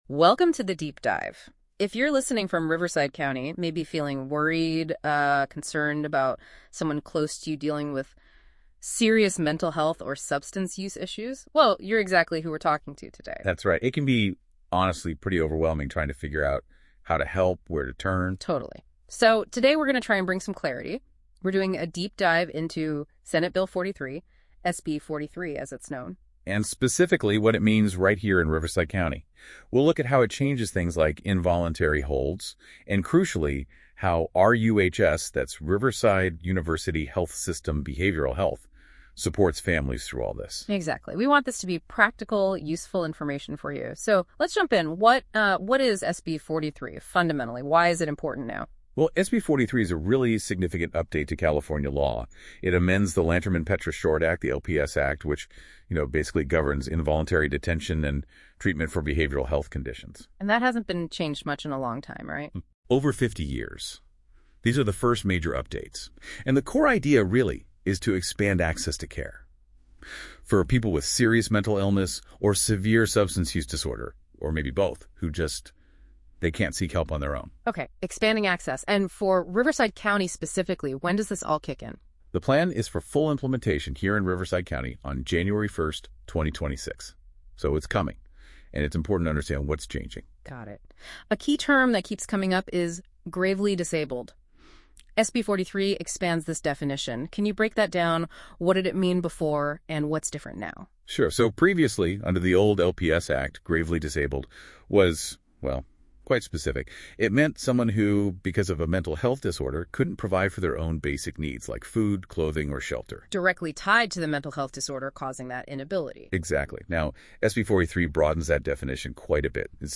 This special conversation explains how SB 43 will be implemented and what it means for families.